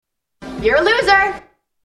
Tags: Prank Calls Sarah Michelle Gellar insults you Sarah Michelle Gellar Actress Celebrity